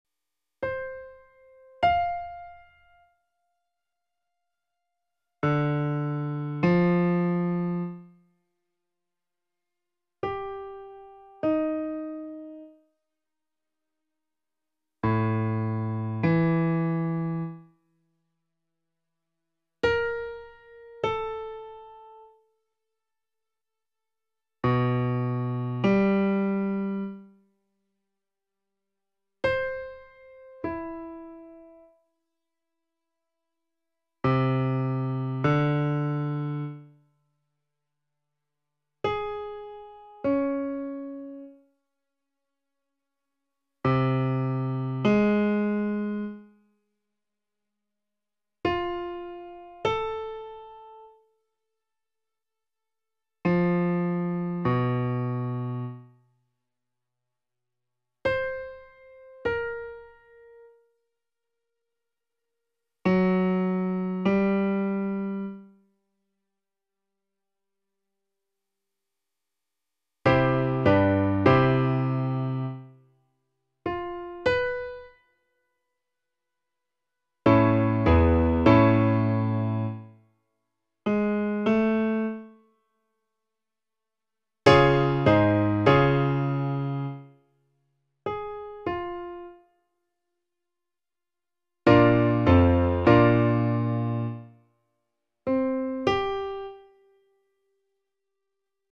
Some are out of context, some will have a key to help with solfège:
intervals.m4a